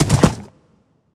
Minecraft Version Minecraft Version 1.21.5 Latest Release | Latest Snapshot 1.21.5 / assets / minecraft / sounds / mob / horse / skeleton / water / gallop1.ogg Compare With Compare With Latest Release | Latest Snapshot
gallop1.ogg